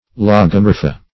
Lagomorpha \Lag`o*mor"pha\ (l[a^]g`[-o]*m[^o]r"f[.a]), prop. n.
lagomorpha.mp3